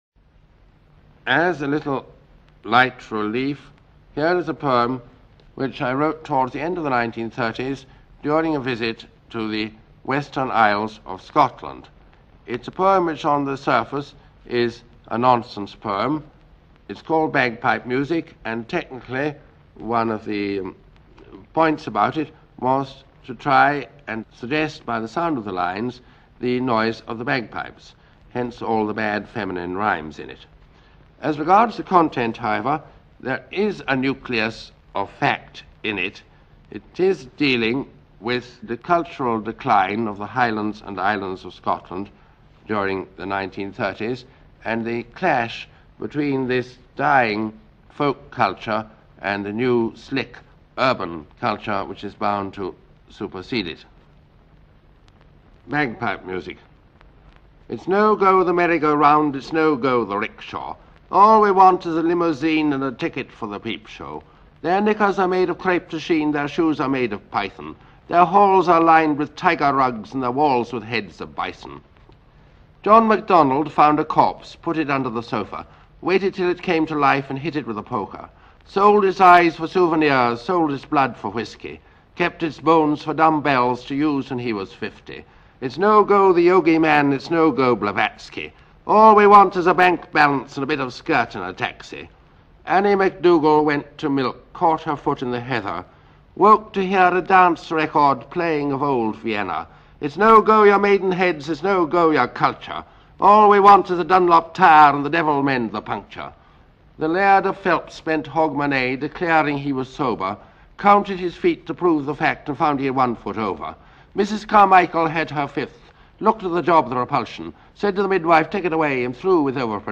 Perhaps I should just start reading aloud words unaccompanied, like